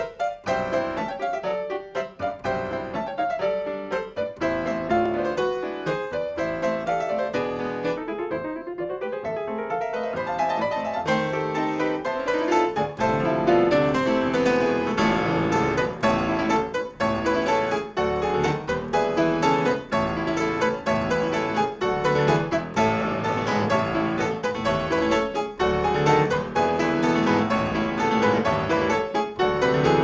Solo Instrumentals